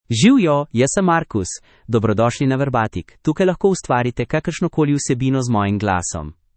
Marcus — Male Slovenian AI voice
Marcus is a male AI voice for Slovenian (Slovenia).
Voice sample
Listen to Marcus's male Slovenian voice.
Male
Marcus delivers clear pronunciation with authentic Slovenia Slovenian intonation, making your content sound professionally produced.